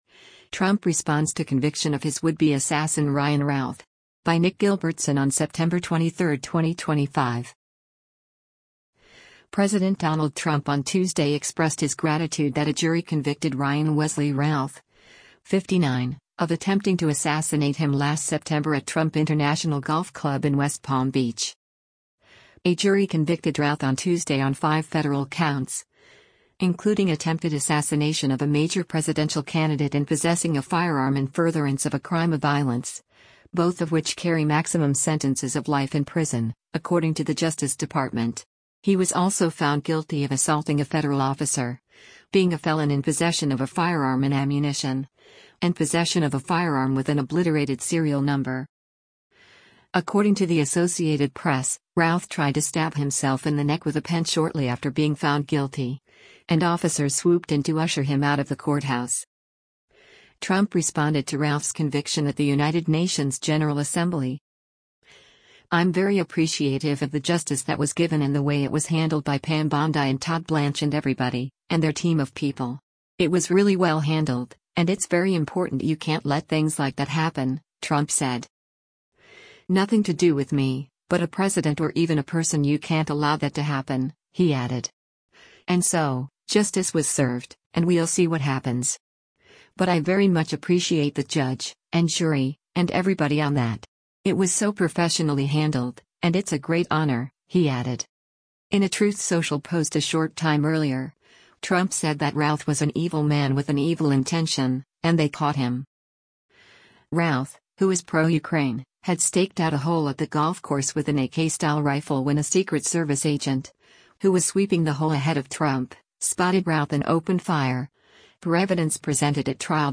Trump responded to Routh’s conviction at the United Nations General Assembly.